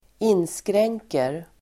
Uttal: [²'in:skreng:ker]
inskr0344nker.mp3